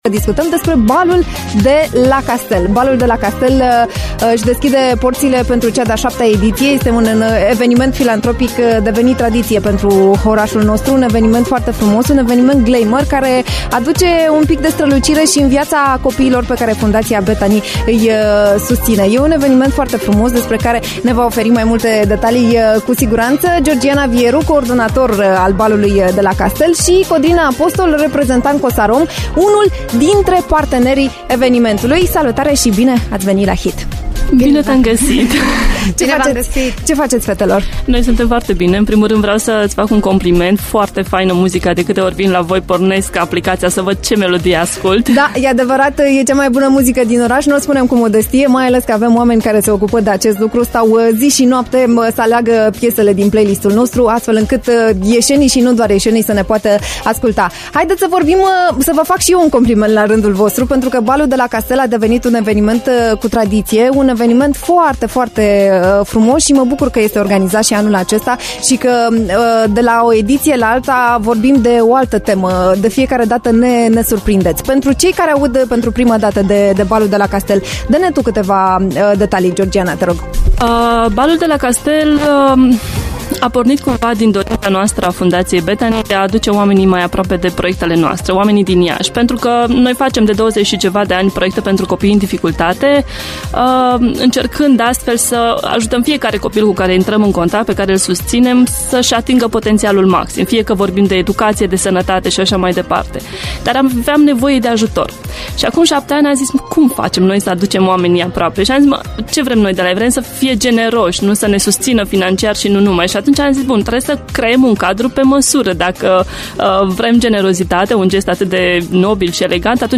In Be the HIT, am stat de vorbă